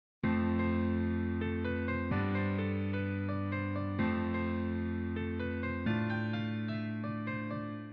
Ideal for producers looking for uplifting and melodic vibes.